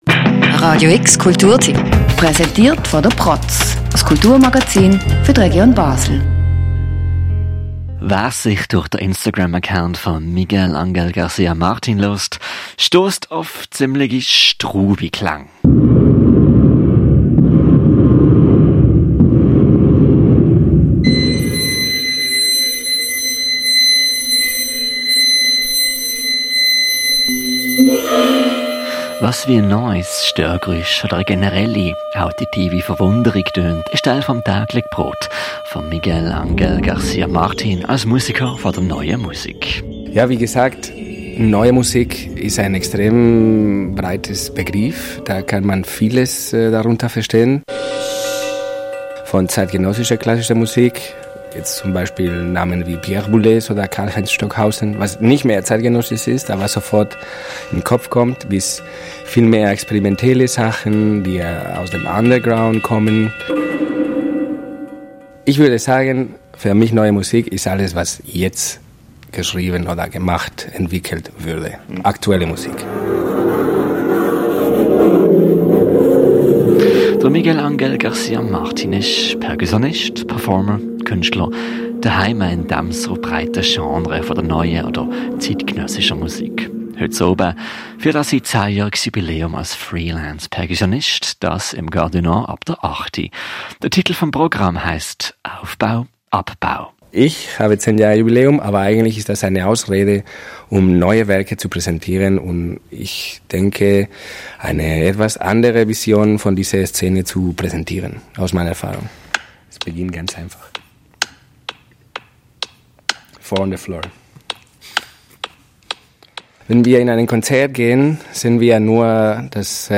Das klingt manchmal skurril, sanft oder